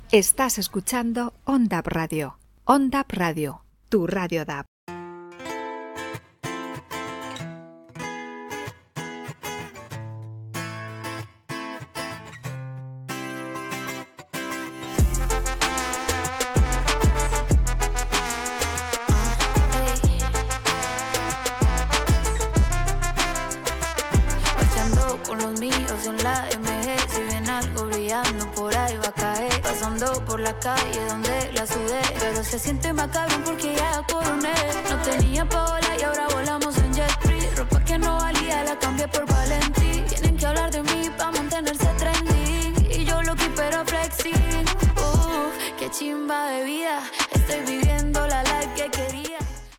Identificació i tema musical